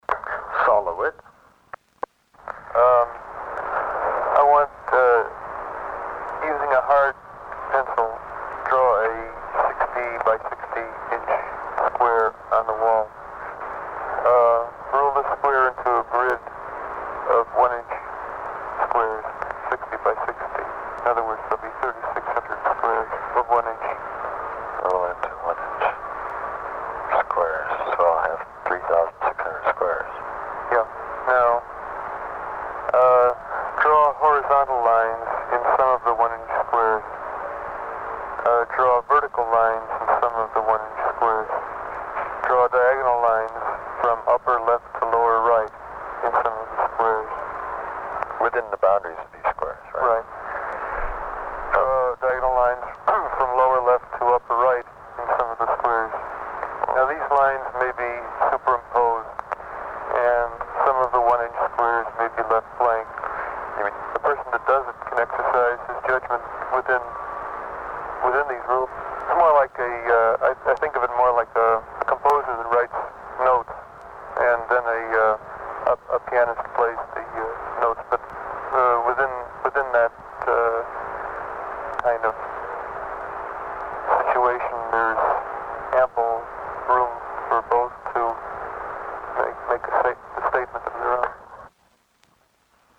audio extrait du vinyle de 1969